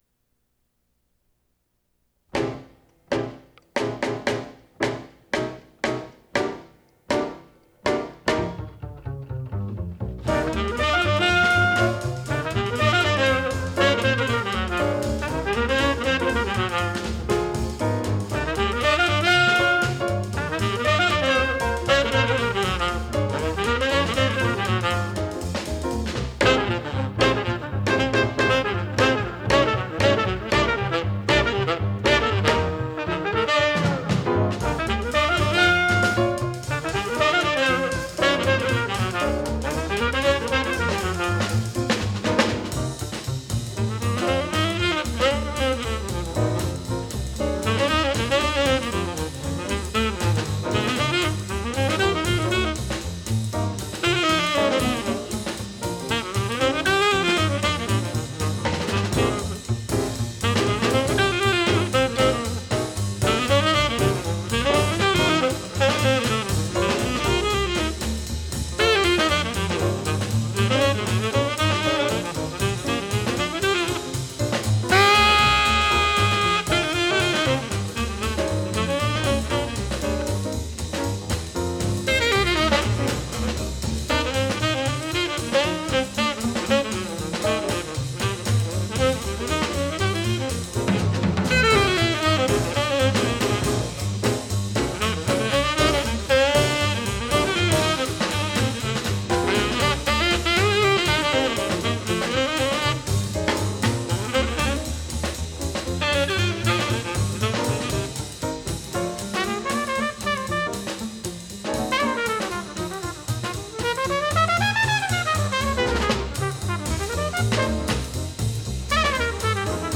Tenor Saxophone
Piano
Trumpet
Vibraphone
Recorded January 1960 in New York City